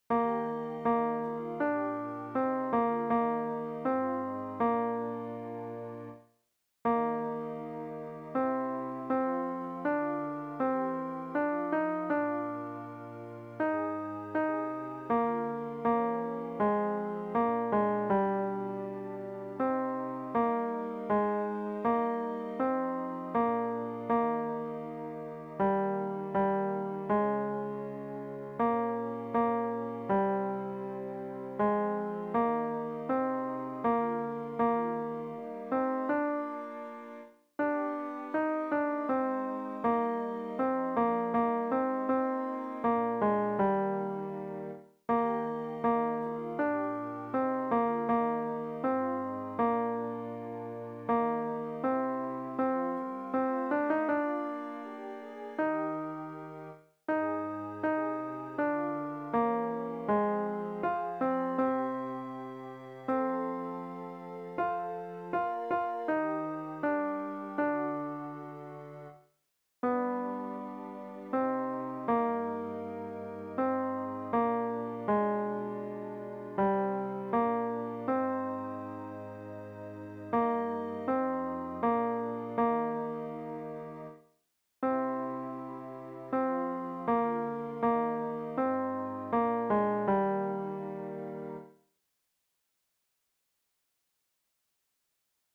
Chorproben MIDI-Files 497 midi files